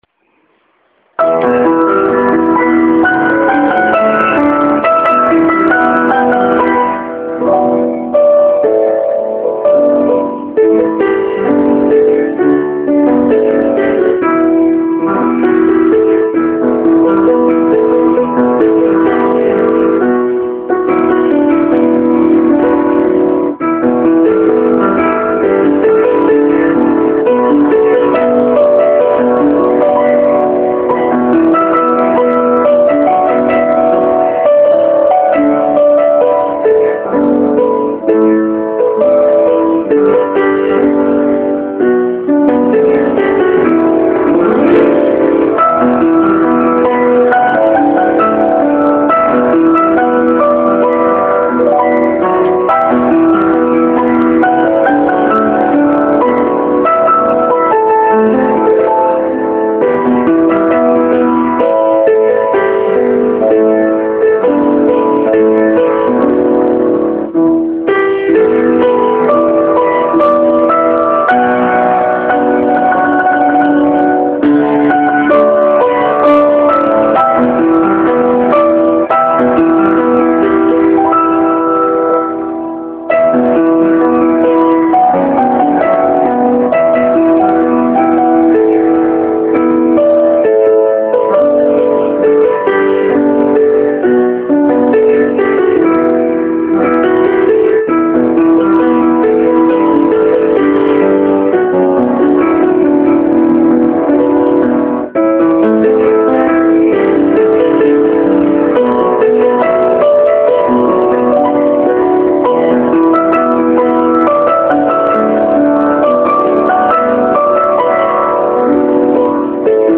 《北斗星》（伴奏